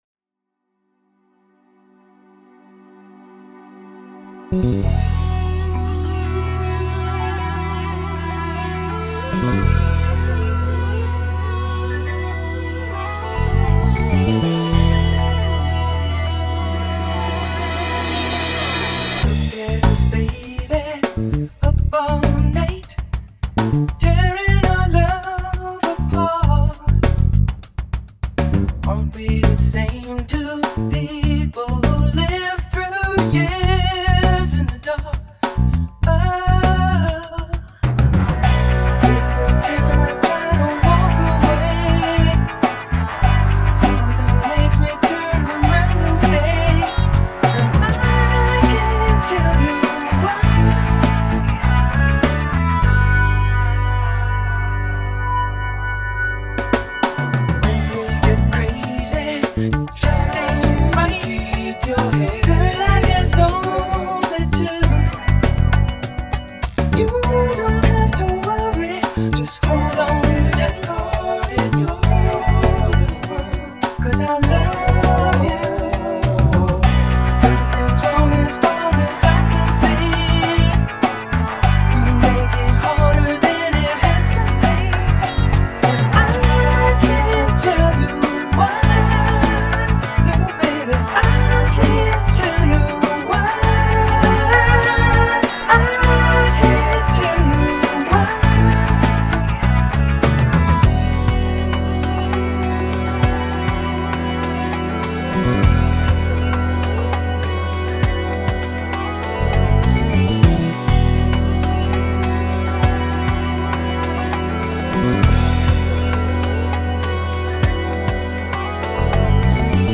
Country Rock nostalgia (and the fight scene)